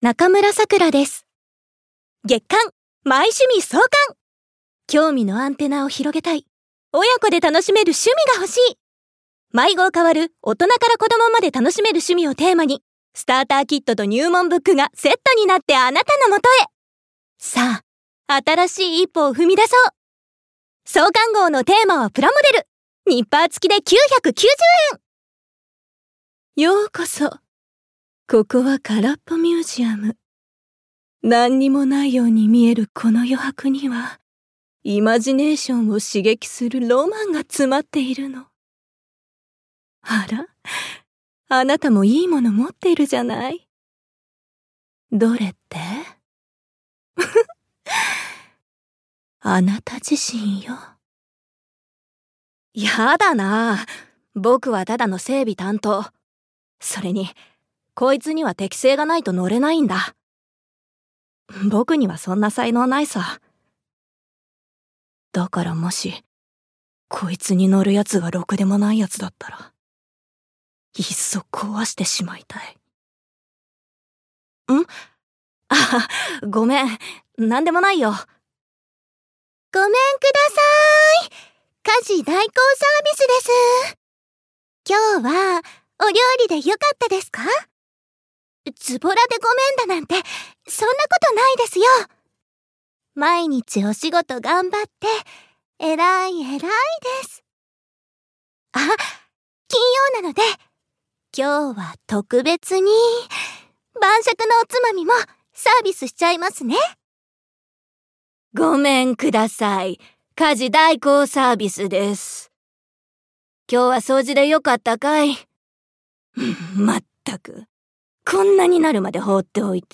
サンプルボイス